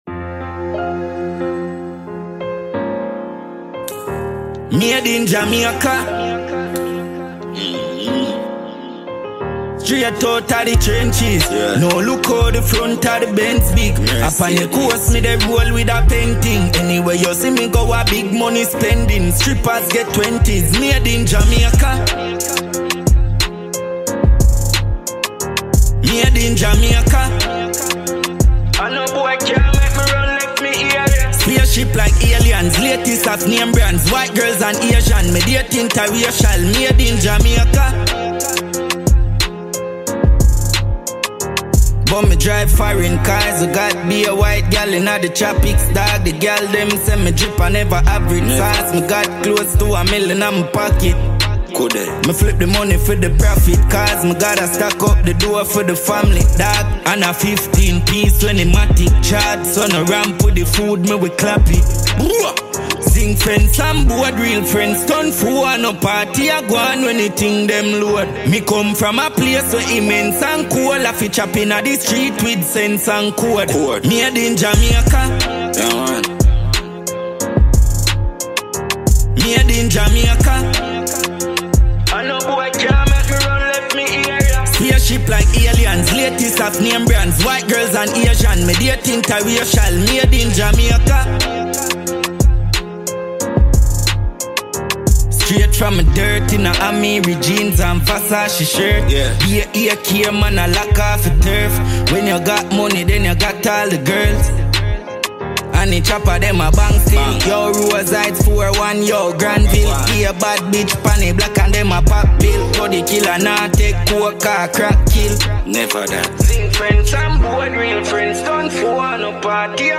Jamaican dancehall recording artist